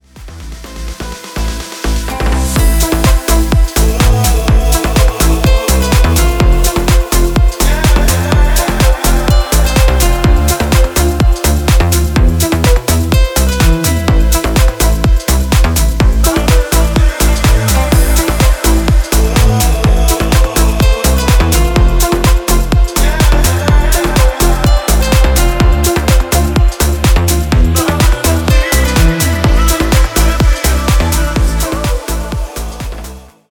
• Качество: 320, Stereo
гитара
Electronic
красивая мелодия
чувственные
Стиль: deep house.